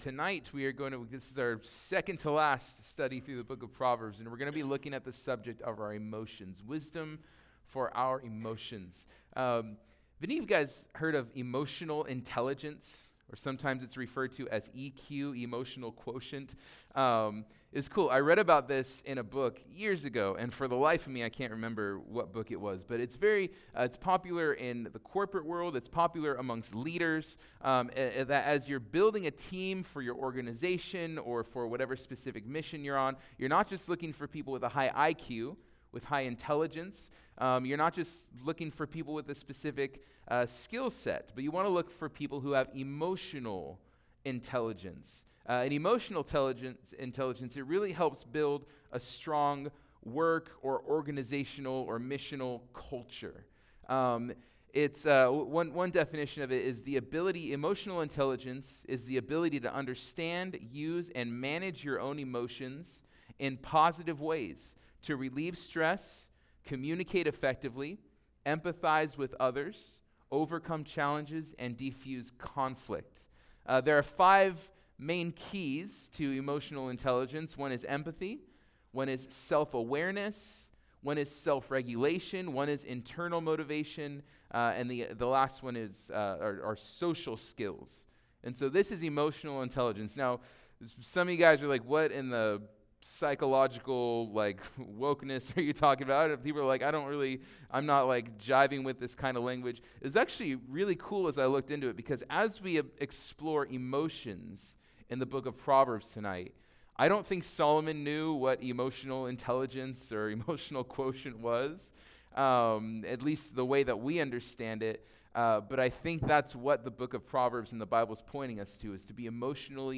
Wisdom+for+Our+Emotions+Midweek+Service.mp3